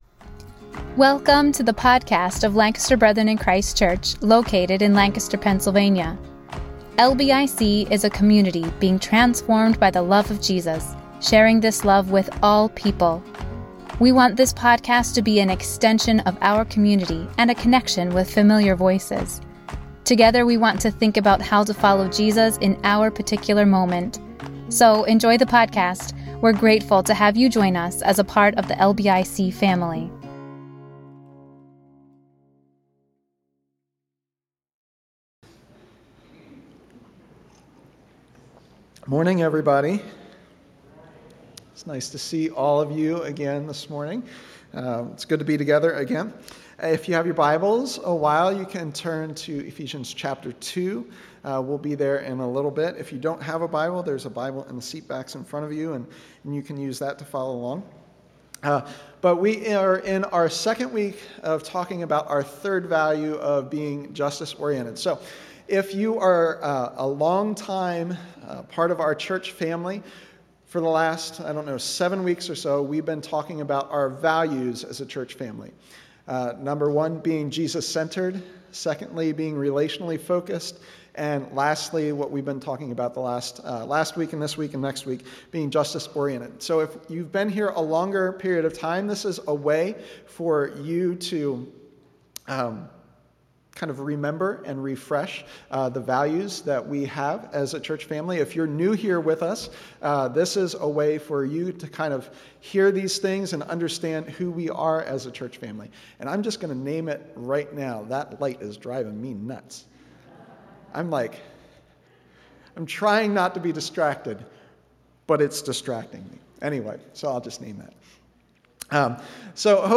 A message from the series "Values."